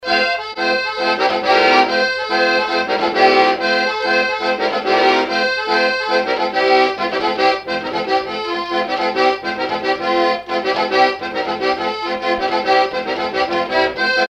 Localisation Noirmoutier-en-l'Île (Plus d'informations sur Wikipedia)
Fonction d'après l'analyste danse : branle : courante, maraîchine ;
Genre brève
Catégorie Pièce musicale inédite